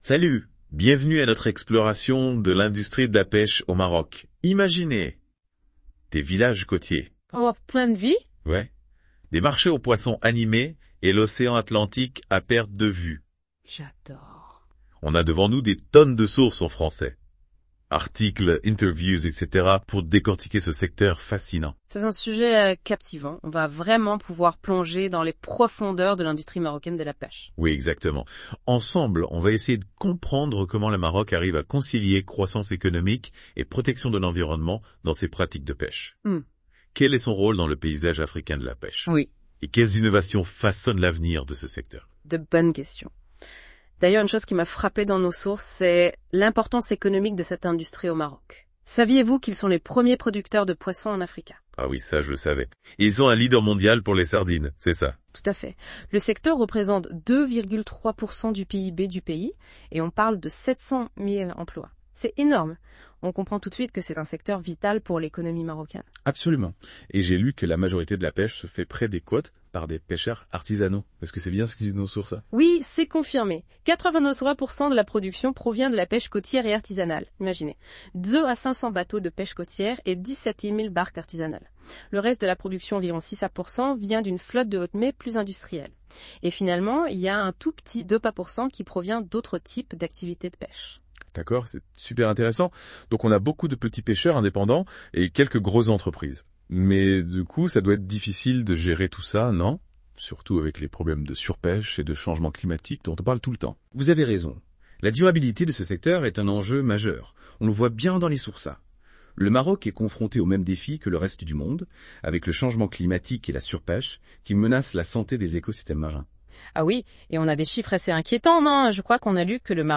Les chroniqueurs de la Web Radio R212 ont lus attentivement l'hebdomadaire économique de L'ODJ Média et ils en ont débattu dans ce podcast